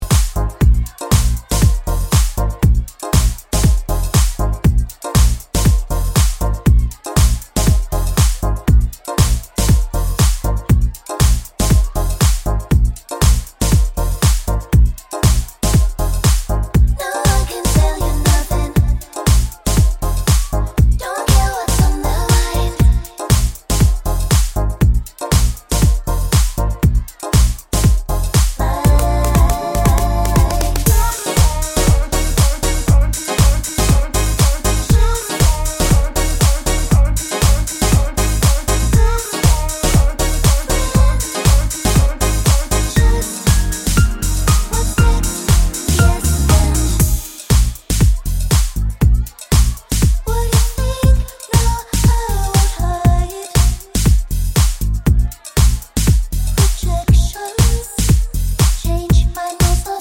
Explicit Backing Vocals Pop (2020s) 3:33 Buy £1.50